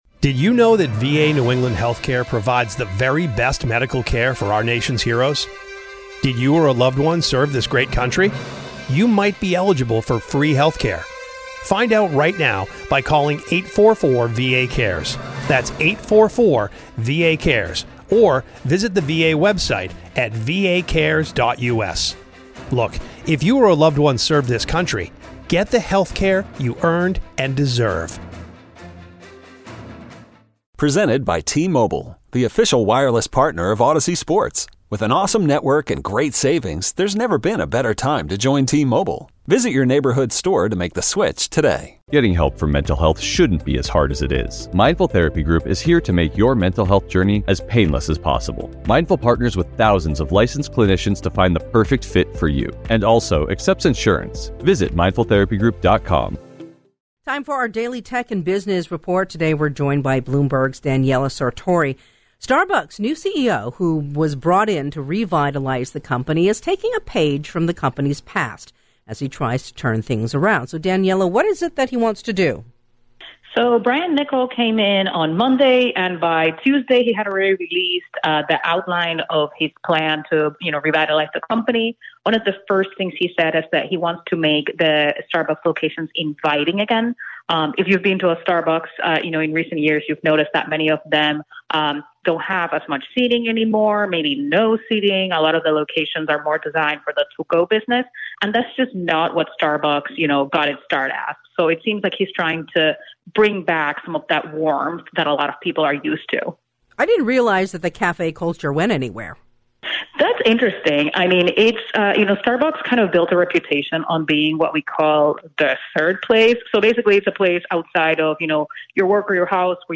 This is KCBS Radio's daily Tech and Business Report.